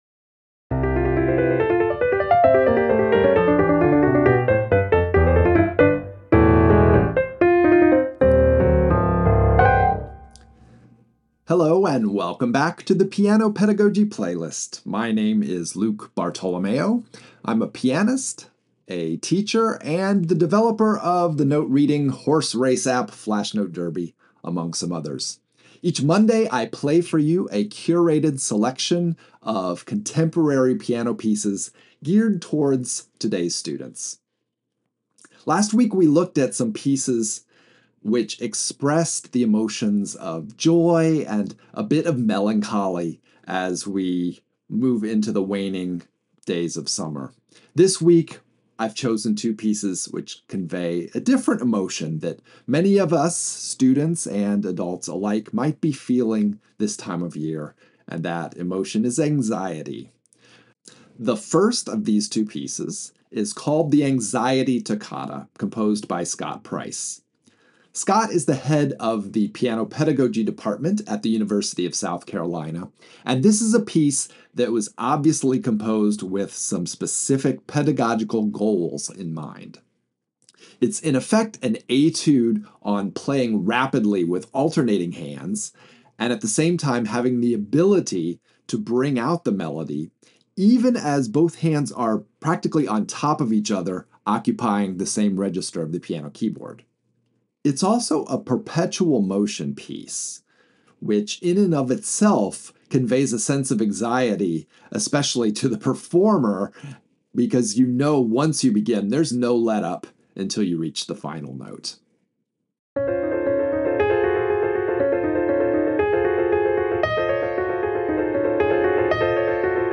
Intermediate piano solo by Scott Price.
Intermediate piano solo by Friedrich Burgmũeller.
First, the Anxiety Toccata by Scott Price, which doesn’t let up from the first note until the double bar. Then I take a spirited dash through Friedrich Burgmüller’s Inquiétude, which offers just enough breathing room to avoid passing out.